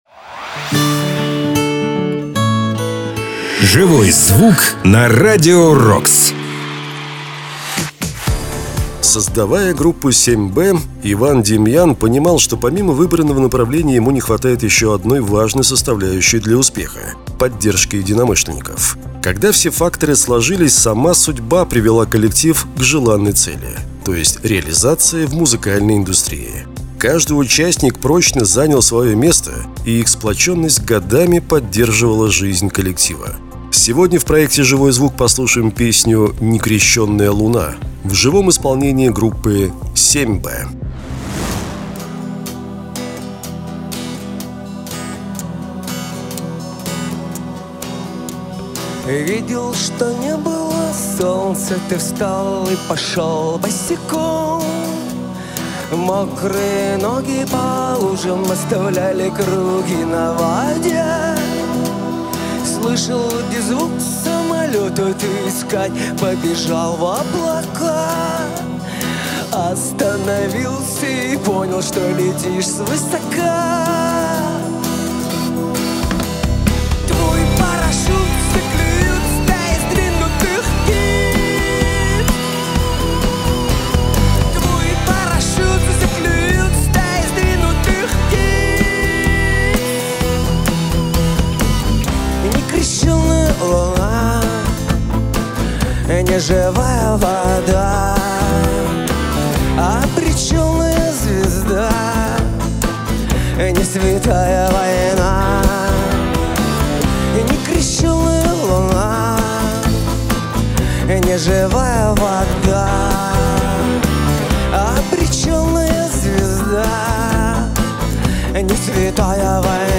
Мы знаем, вам интересны живые выступления известных и популярных артистов!